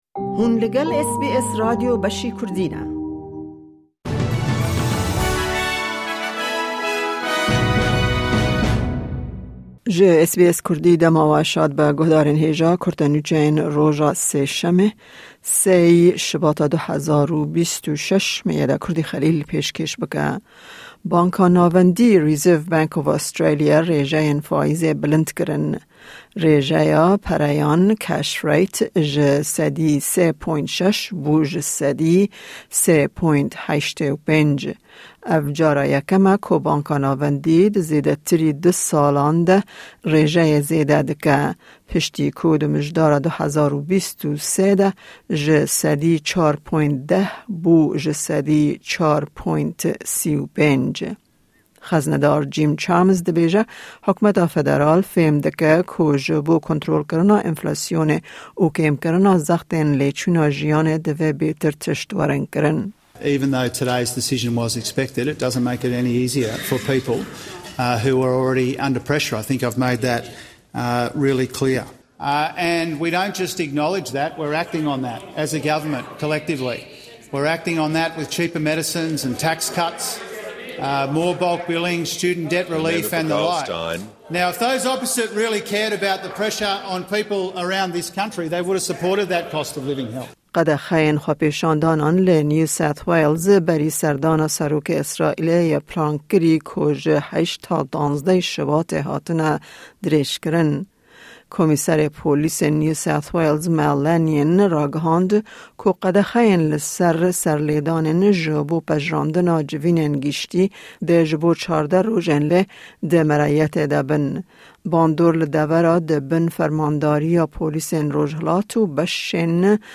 Nûçeyên roja Sêşemê 03/02/2026